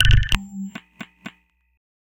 Abstract Rhythm 09.wav